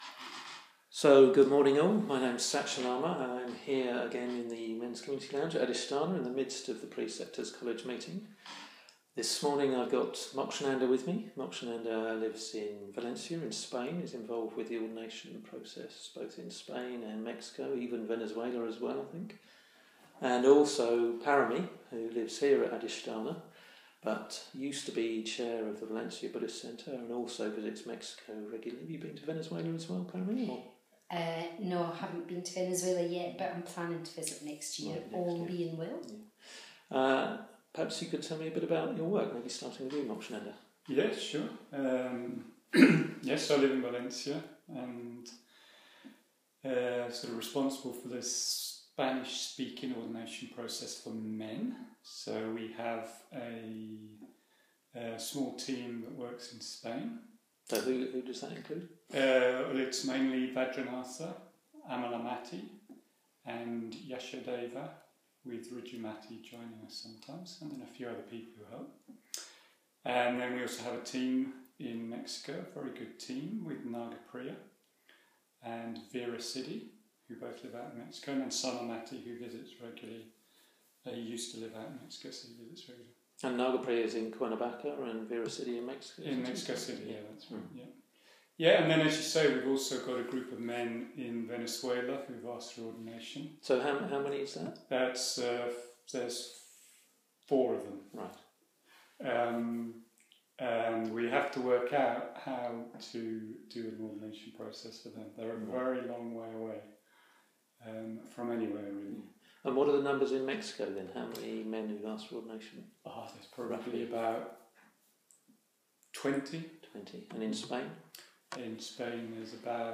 In one of two interviews covering different areas